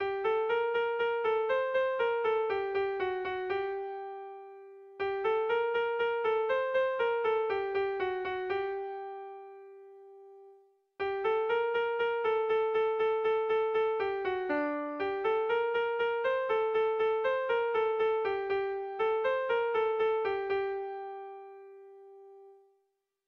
Erlijiozkoa
Zortziko ertaina (hg) / Lau puntuko ertaina (ip)
AAB1B2